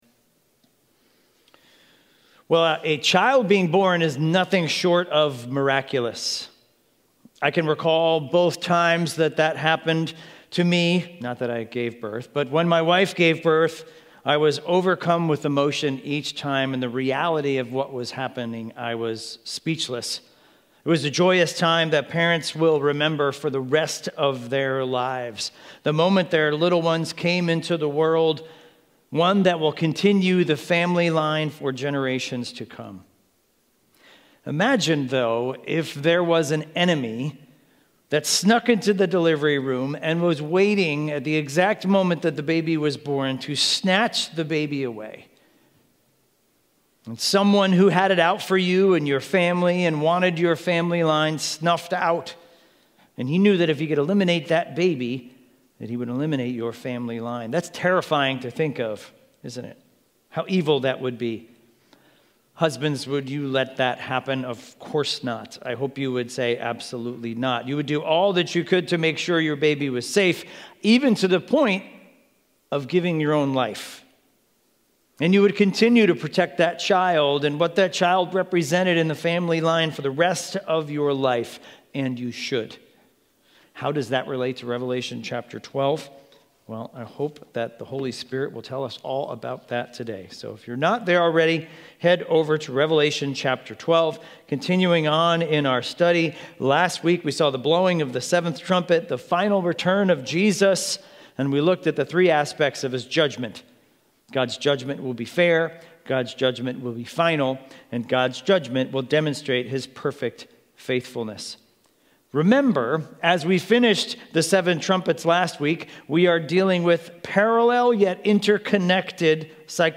Sermons – Highlands Bible Church
Expositional preaching series through the book of Revelation.